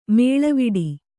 ♪ mēḷaviḍi